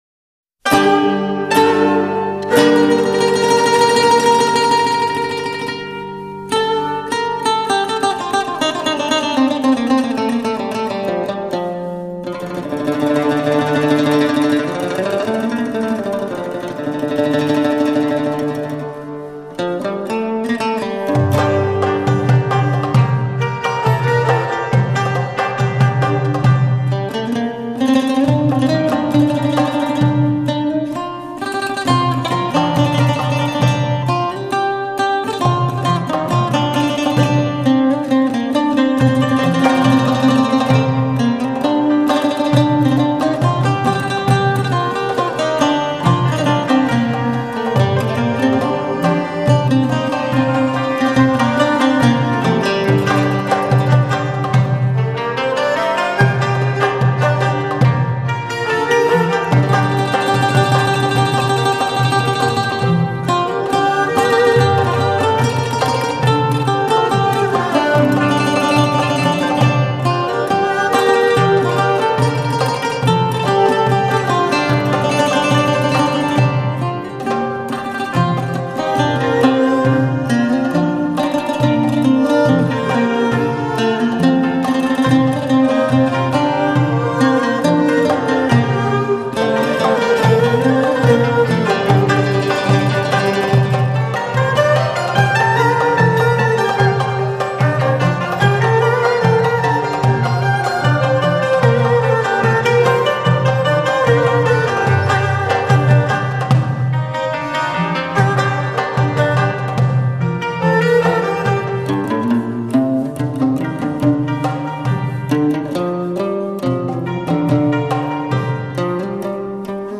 类型: 民乐雅韵
中阮